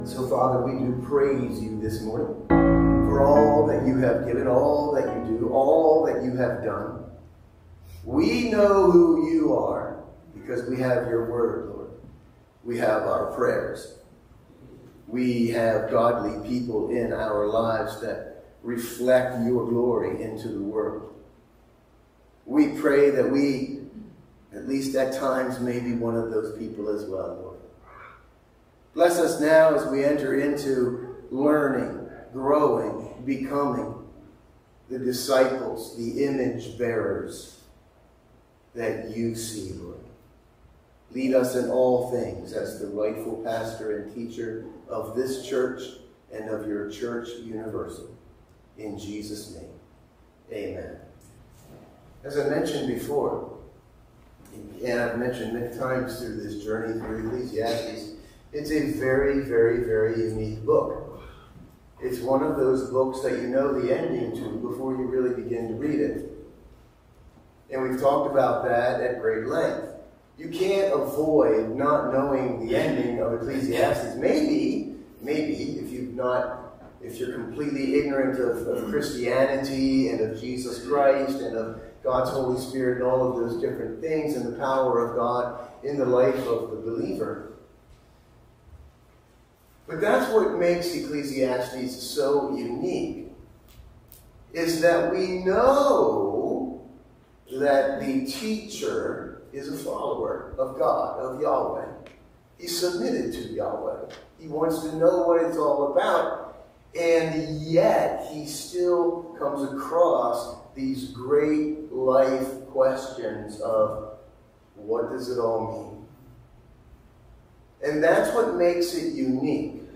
Sunday Morning Service – August 4, 2024
Sunday-Morning-Service-August-4-2024.mp3